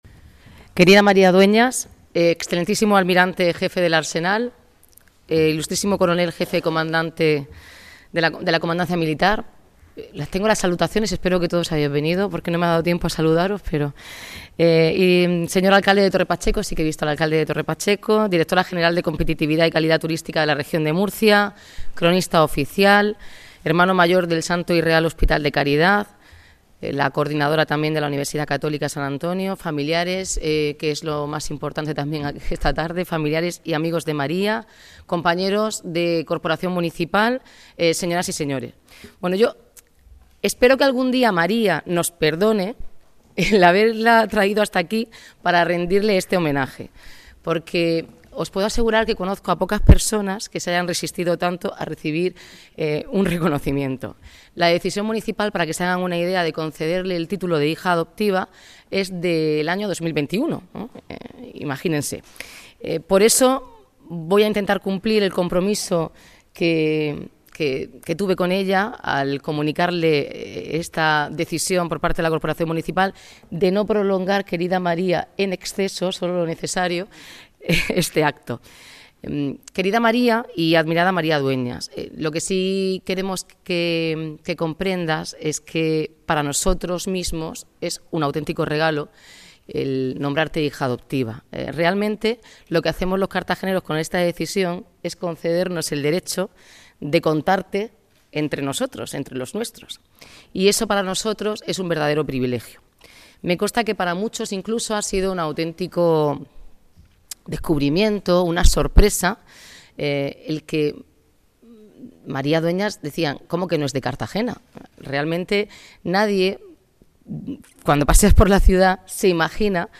Audio: Acto de nombramiento de María Dueñas como Hija Adoptiva de Cartagena. (MP3 - 12,88 MB)